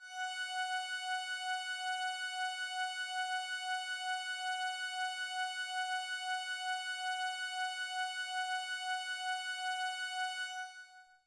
Roland Juno 6 Open Pad " Roland Juno 6 Open Pad F6 ( Open Pad90127IERD)
标签： F6 MIDI音符-90 罗兰朱诺-6 合成器 单票据 多重采样
声道立体声